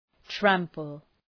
Shkrimi fonetik {‘træmpəl}